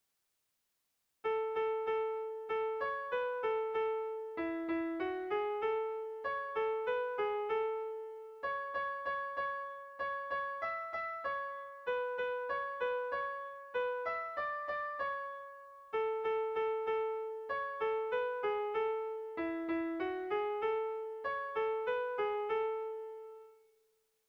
Gabonetakoa
ABA